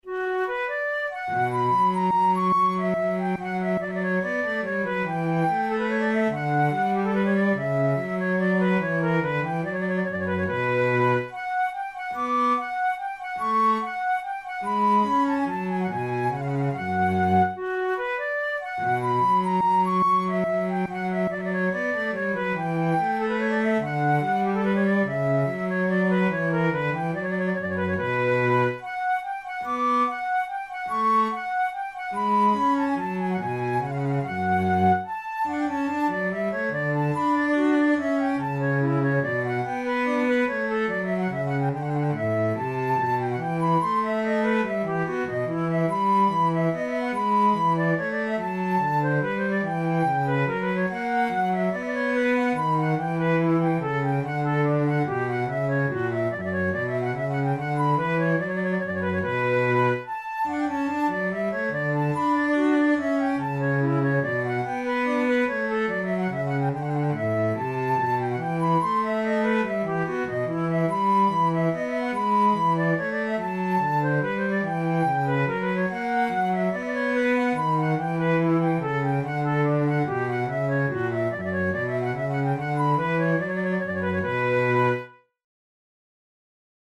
InstrumentationFlute and bass instrument
KeyB minor
Time signature3/4
Tempo144 BPM
Baroque, Sonatas, Written for Flute